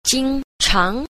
4. 經常 – jīngcháng – kinh thường (thông thường, đều đặn)